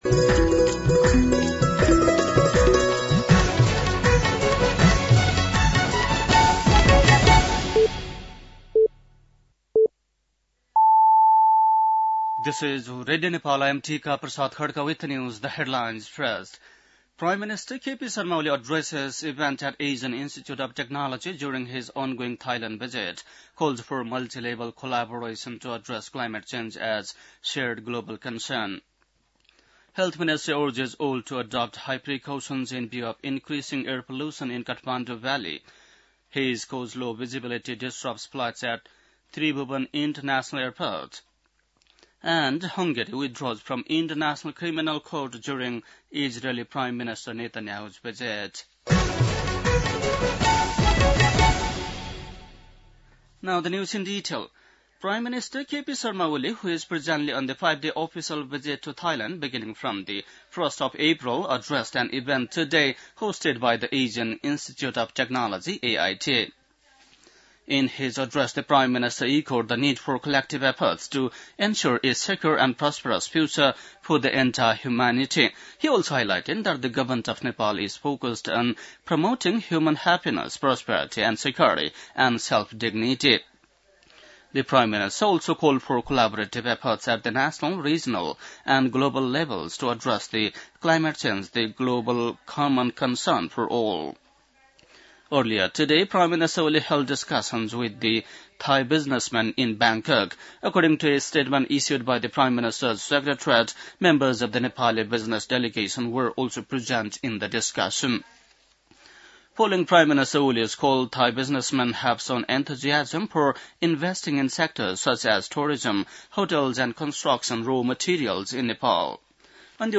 बेलुकी ८ बजेको अङ्ग्रेजी समाचार : २१ चैत , २०८१